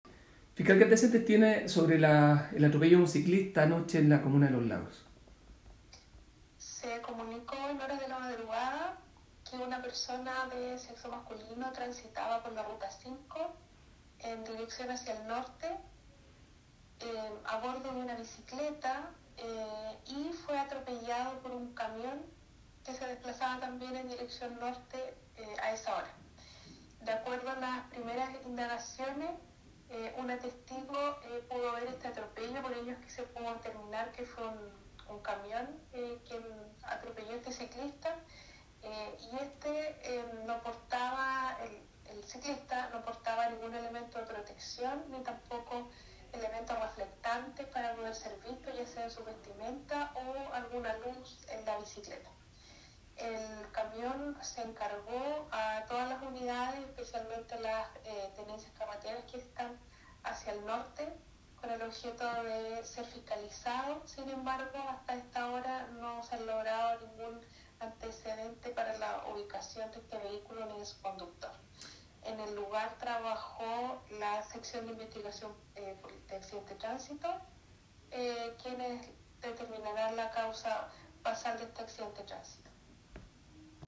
Fiscal Claudia Baeza…